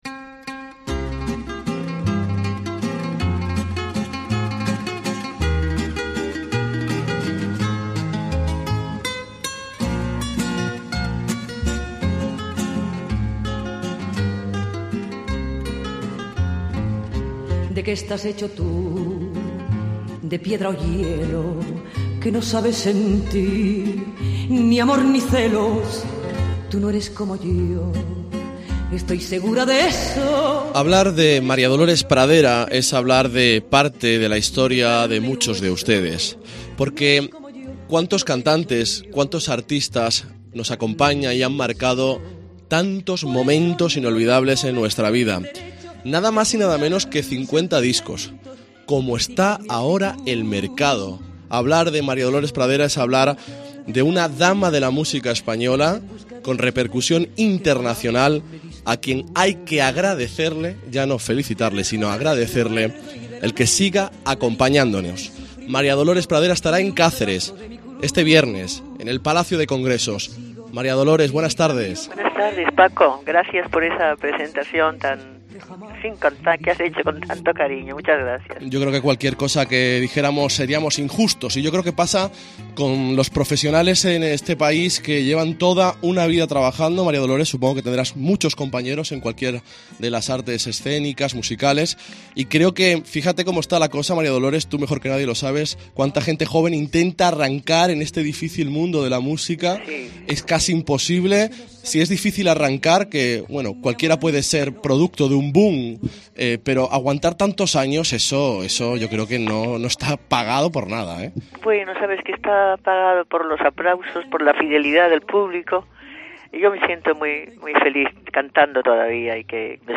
Última entrevista a María Dolores Pradera en COPE Extremadura